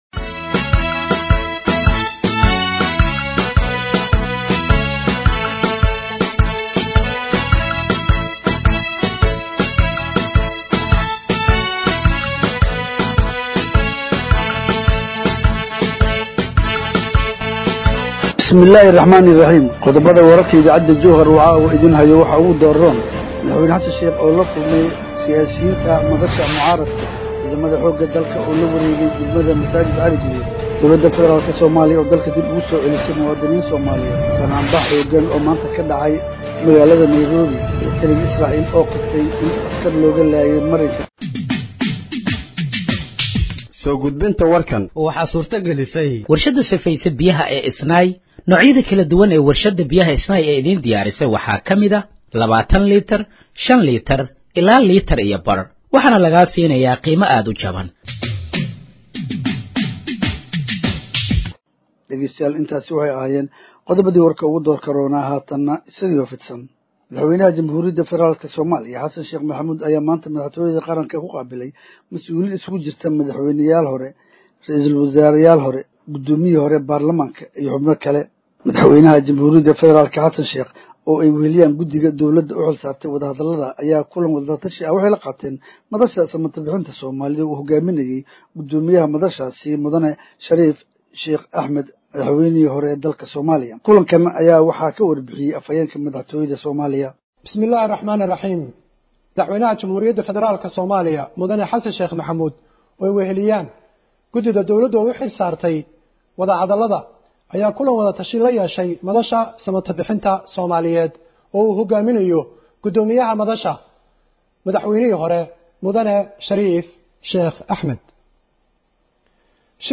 Dhageeyso Warka Habeenimo ee Radiojowhar 25/06/2025